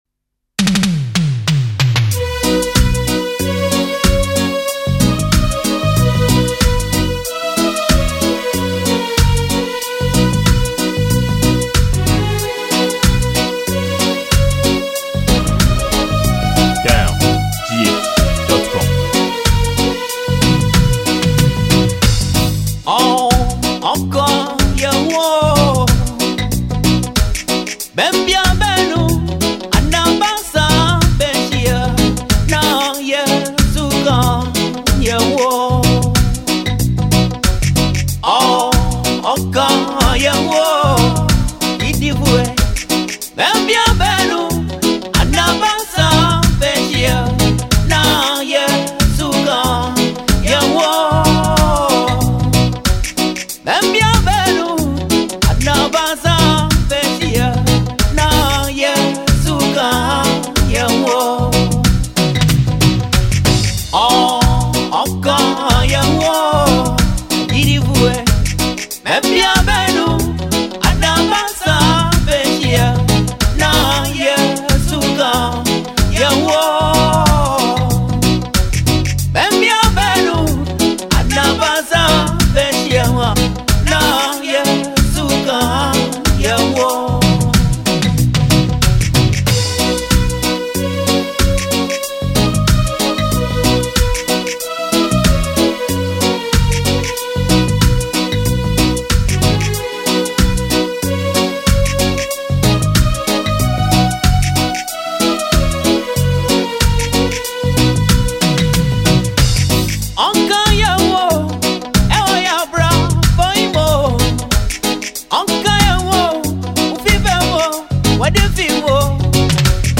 a throwback song released in late 90s.